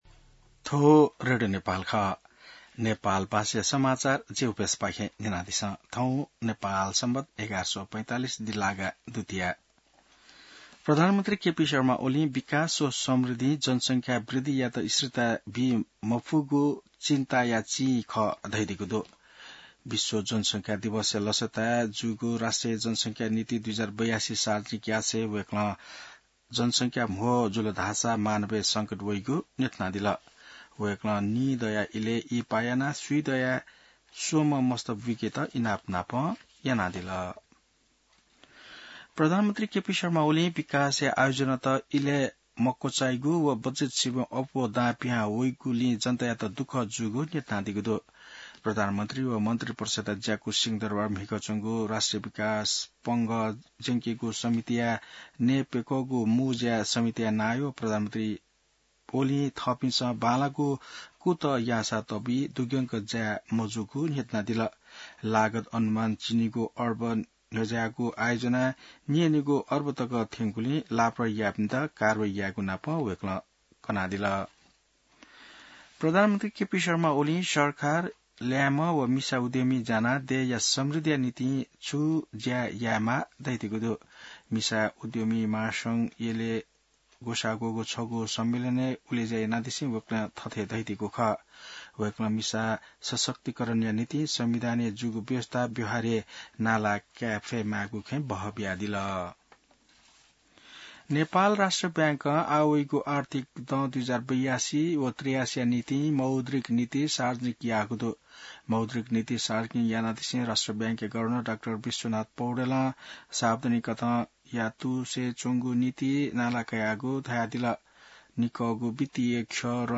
नेपाल भाषामा समाचार : २८ असार , २०८२